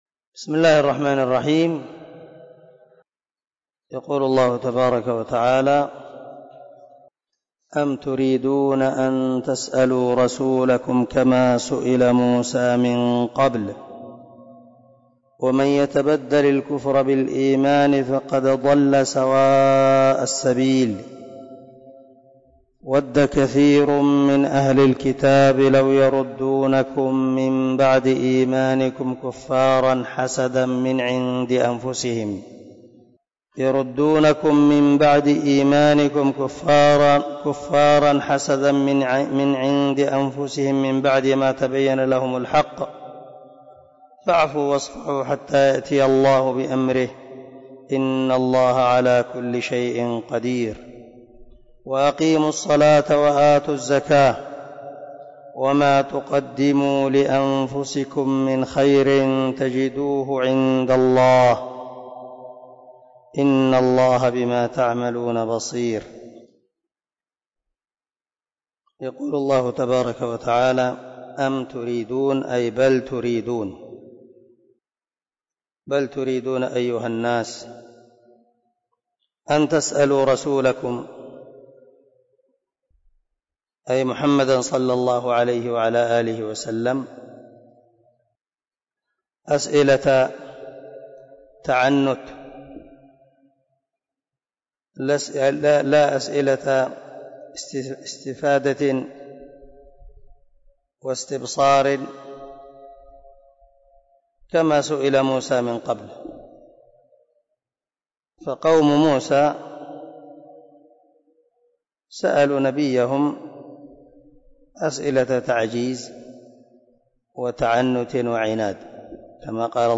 045الدرس 35 تفسير آية ( 108 - 110 ) من سورة البقرة من تفسير القران الكريم مع قراءة لتفسير السعدي